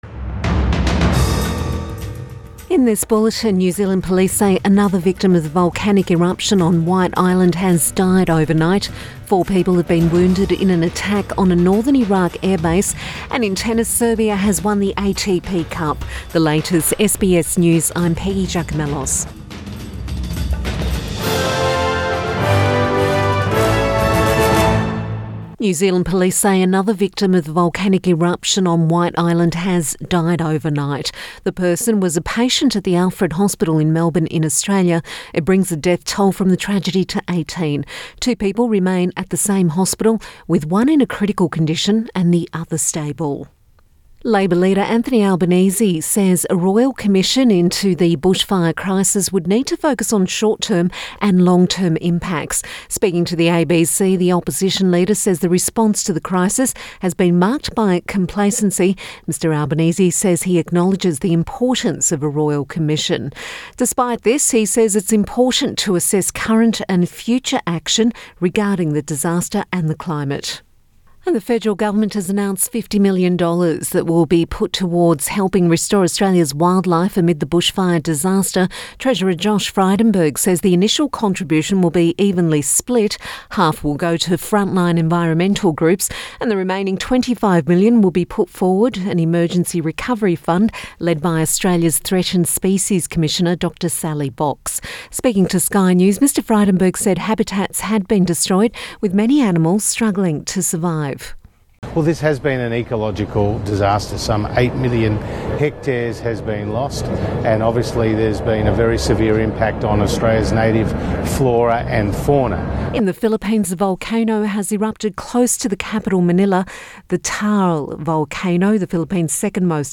AM bulletin January 13 2020